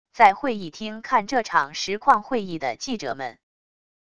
在会议厅看这场实况会议的记者们wav音频